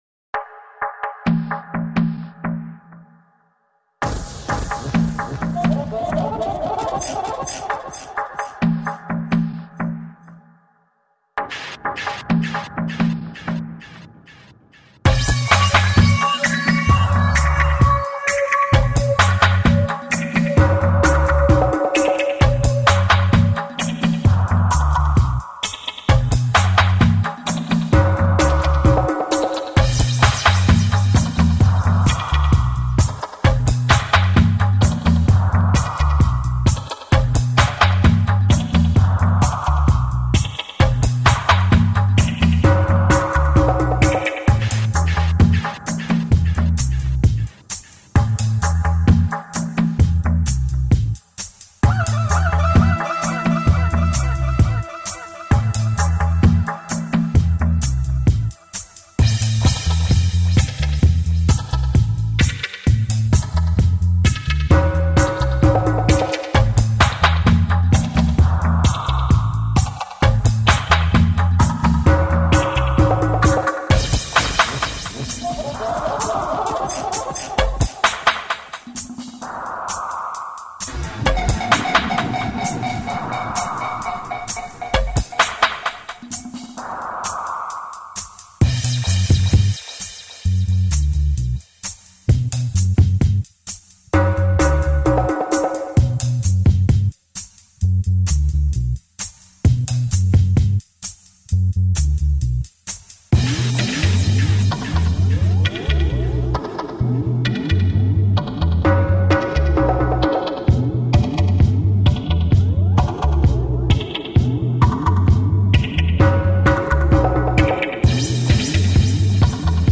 subdued and seductive